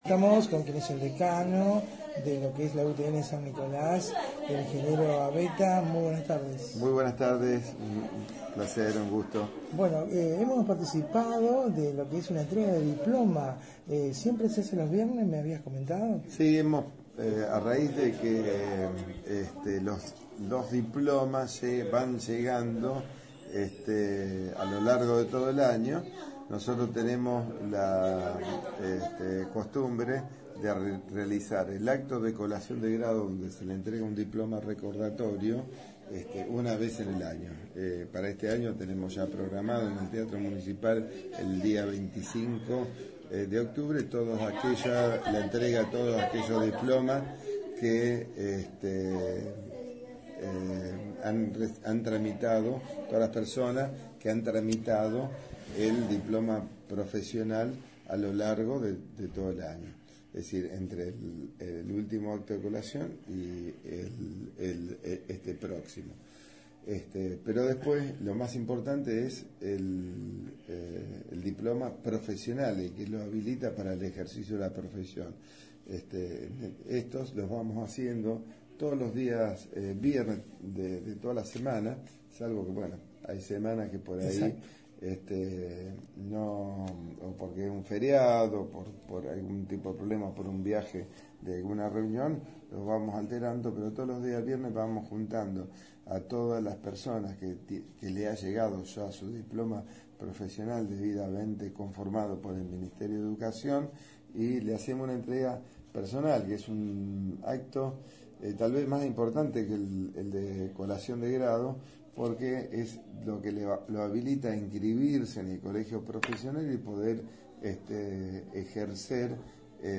Audio: Charla con el Ing.